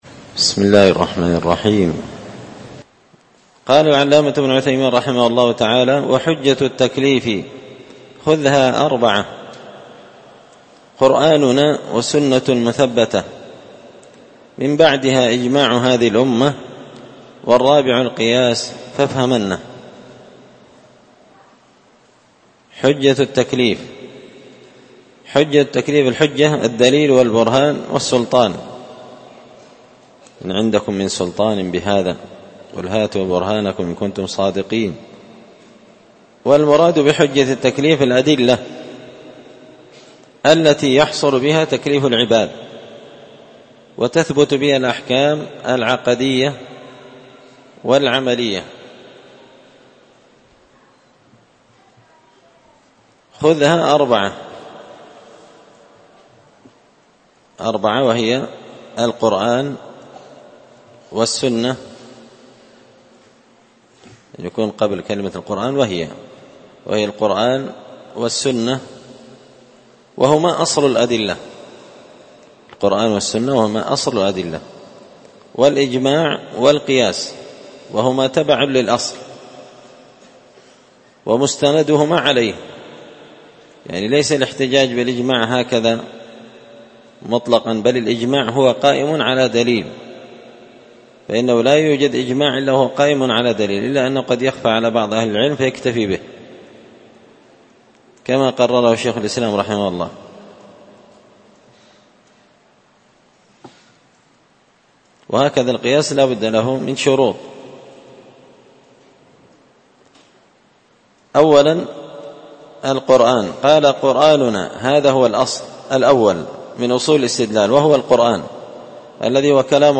تسهيل الوصول إلى فهم منظومة القواعد والأصول ـ الدرس 27
مسجد الفرقان